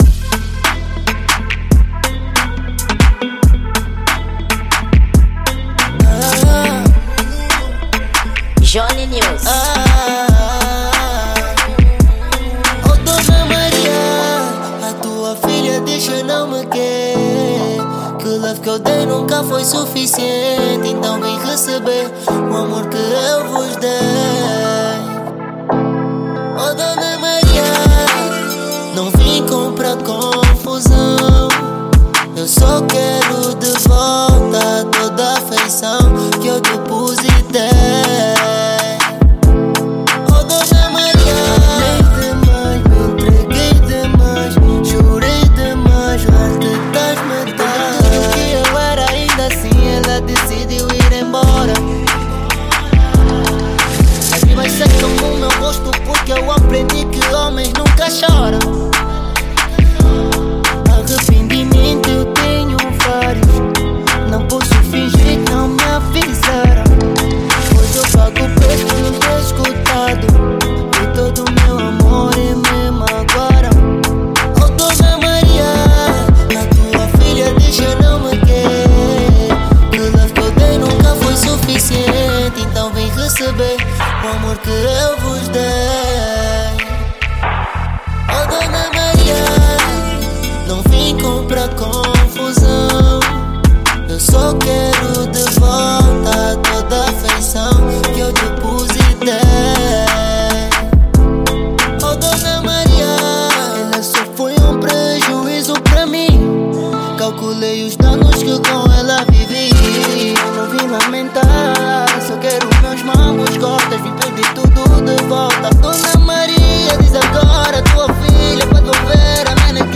Gênero: Afro Trap